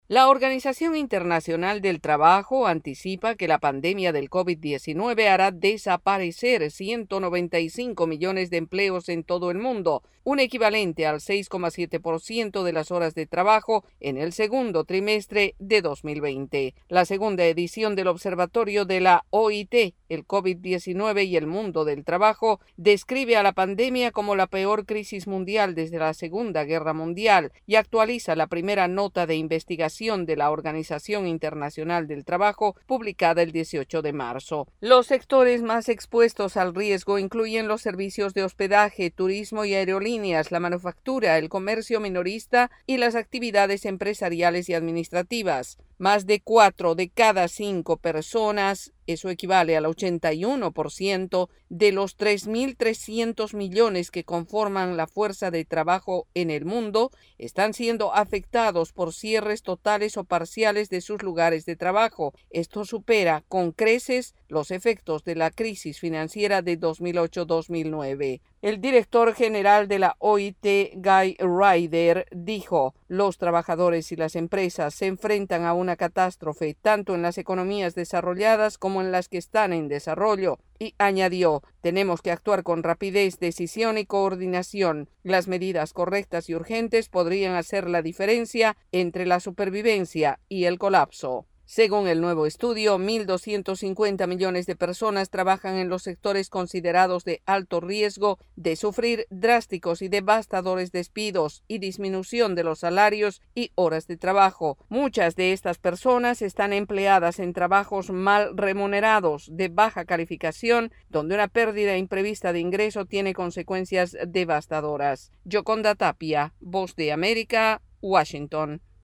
La Organización Internacional del Trabajo advierte sobre la pérdida de millones de empleos por la pandemia del COVID 19 y asegura que solo medidas urgentes e inteligentes evitarán una catástrofe. El informe